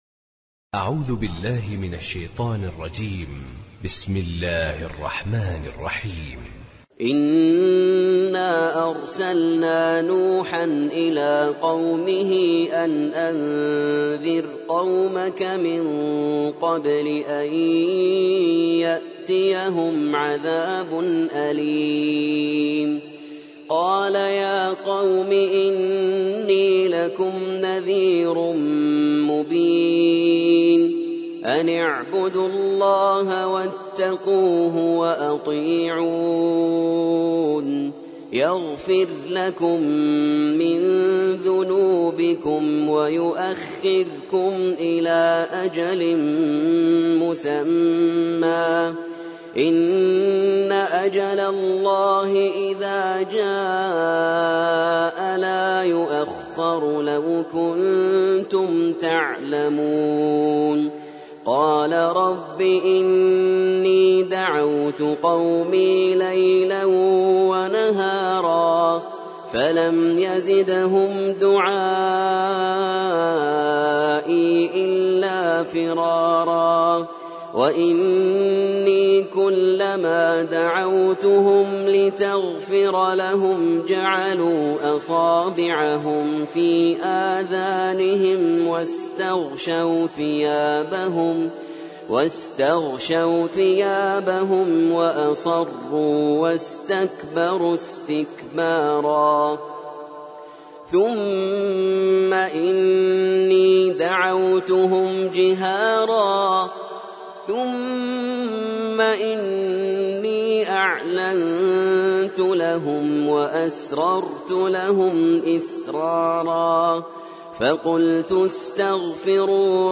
سورة نوح - المصحف المرتل (برواية حفص عن عاصم)
جودة عالية